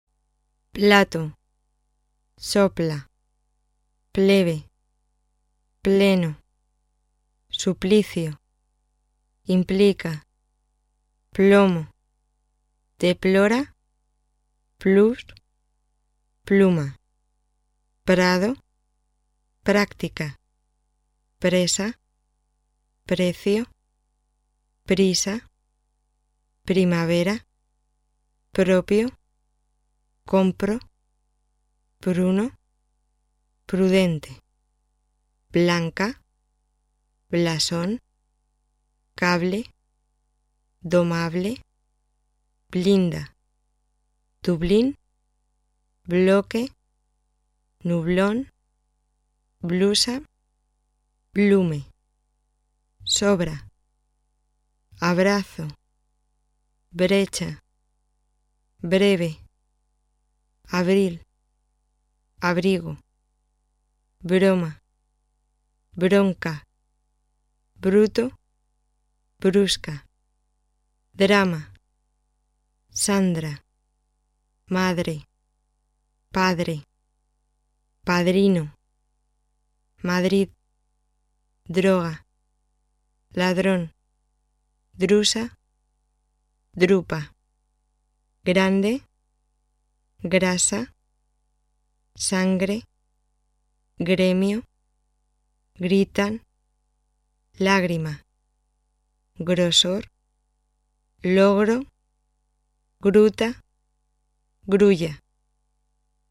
辅音l与r紧接在某些辅音之后构成辅音连缀。发音时，注意避免在两个辅音之间插入一个元音。
【辅音连缀发音】